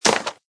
plasticstone2.mp3